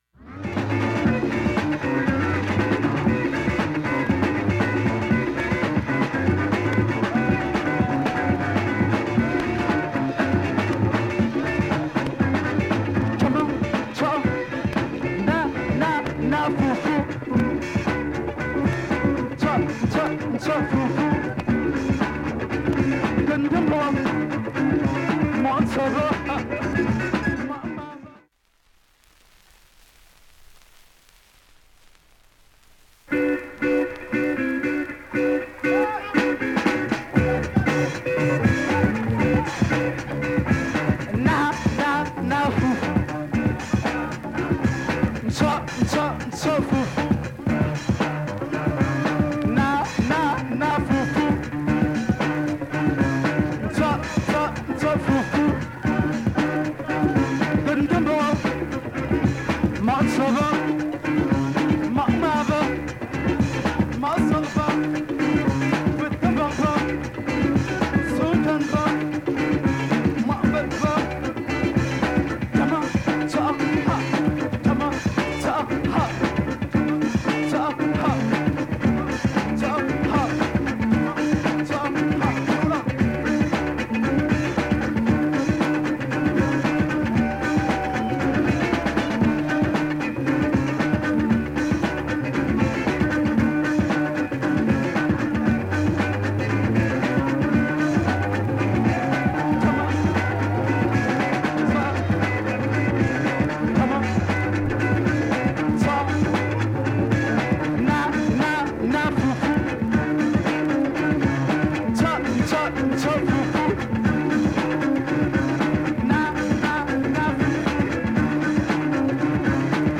◆'73 USA盤 オリジナル 7"Single 45 RPM
（多分針飛び補修）底を通る際に１２回の
かすかなチャッという音が出ます。
現物の試聴（上記箇所と両面すべて録音時間８分２２秒）できます。
中盤に激渋ブレイク